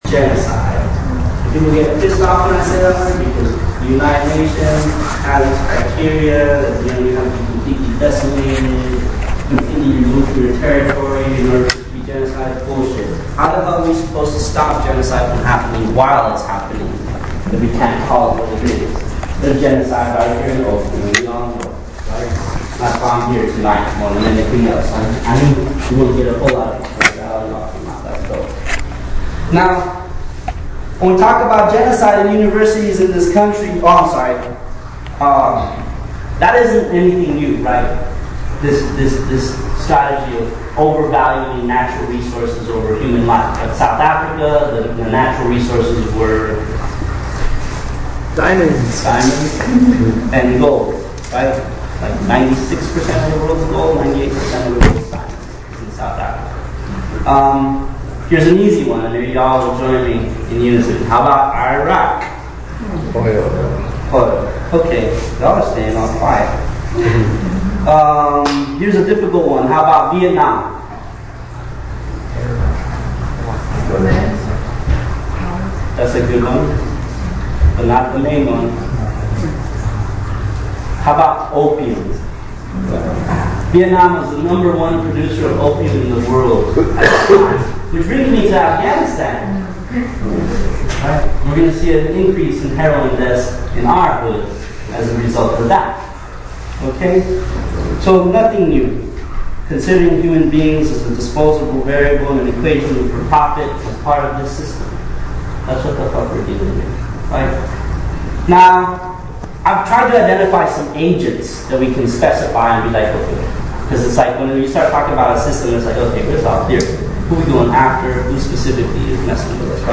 This audio picks up a few minutes into his talk.